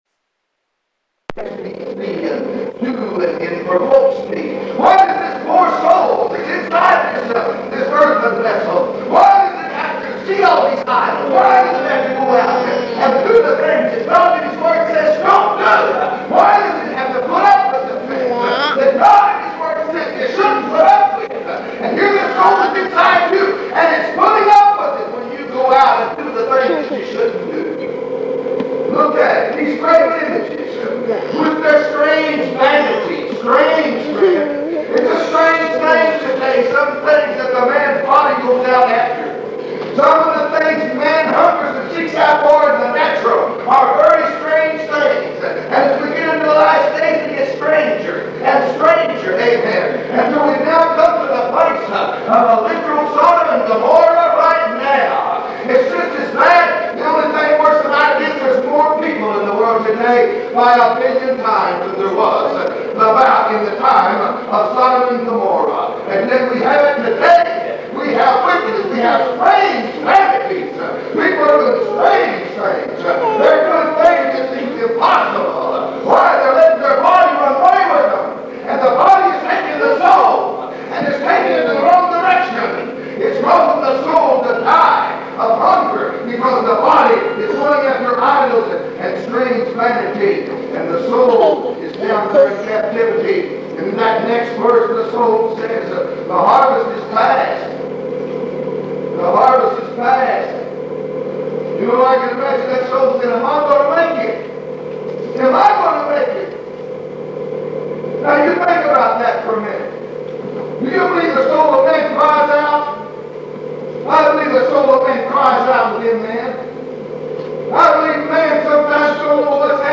All Sermons on Pentecostal Gold are the exclusive property of the copyright owner.